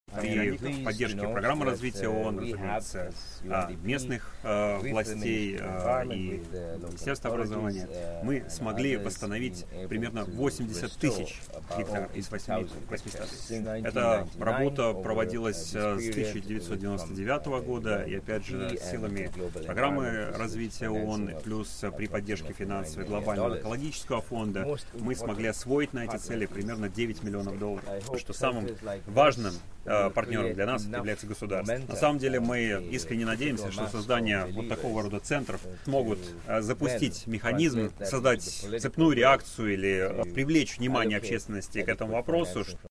Рассказывает постоянный координатор ООН в Беларуси Санака Самарасинха.